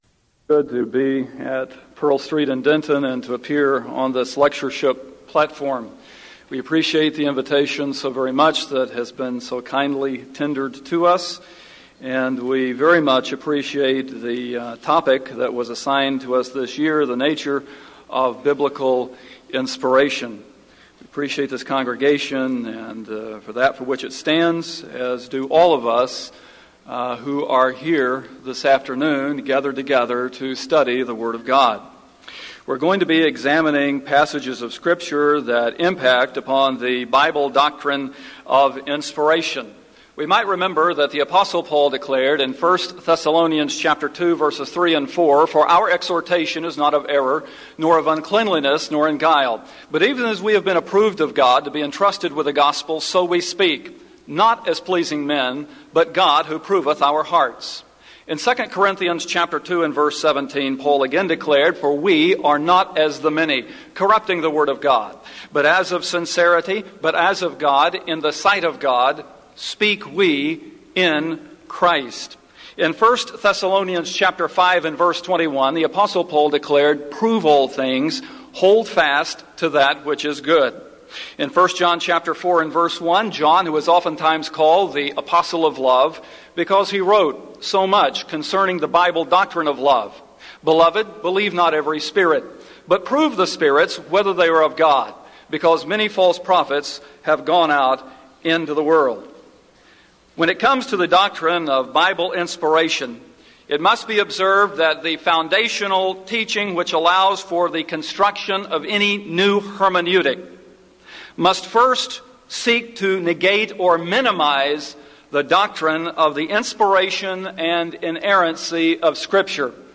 Title: DISCUSSION FORUM: The Nature Of Biblical Inspiration
Event: 1998 Denton Lectures